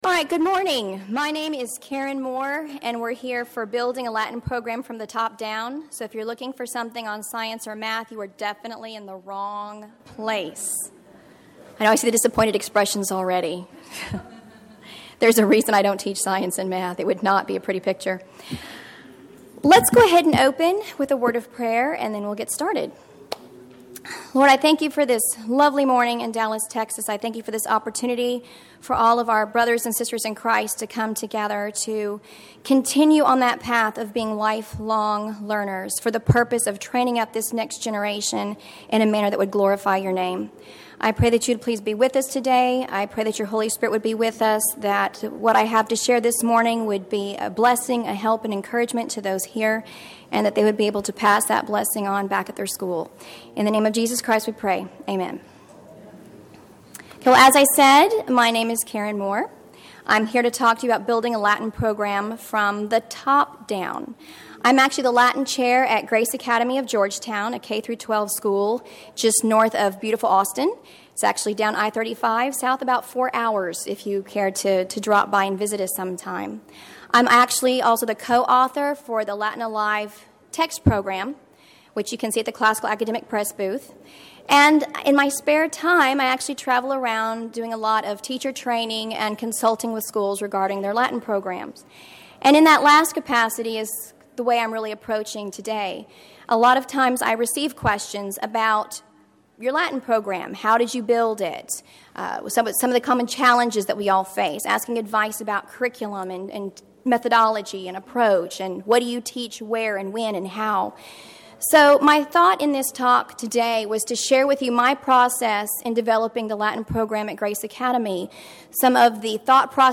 2012 Workshop Talk | 0:53:17 | All Grade Levels, Latin, Greek & Language
Jan 24, 2019 | All Grade Levels, Conference Talks, Latin, Greek & Language, Library, Media_Audio, Workshop Talk | 0 comments